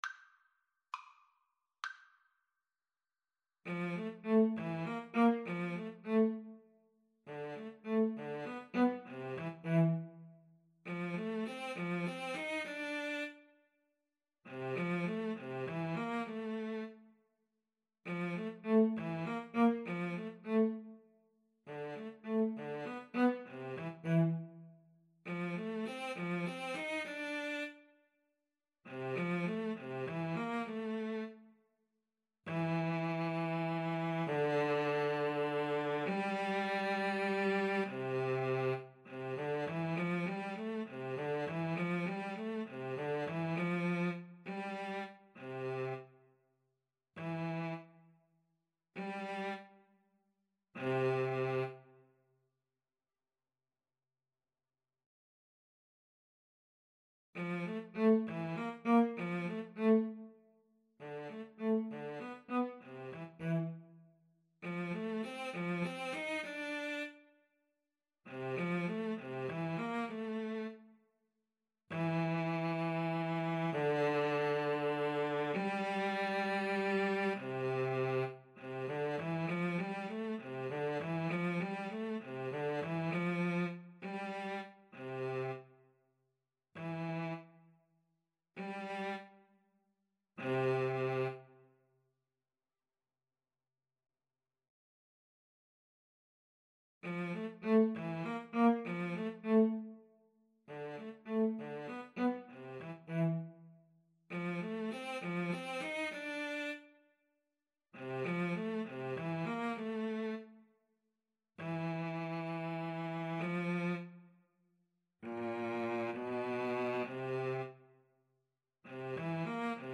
Free Sheet music for Clarinet-Cello Duet
6/8 (View more 6/8 Music)
Allegretto
Classical (View more Classical Clarinet-Cello Duet Music)